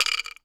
wood_block_rattle_movement_01.wav